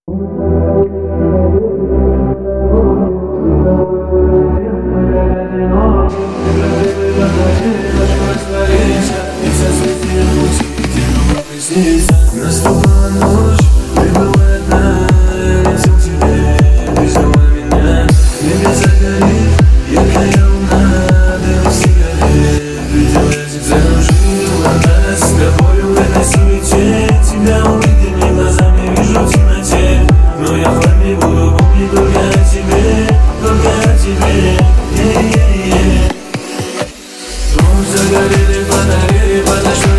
Красивый ритмичный рингтон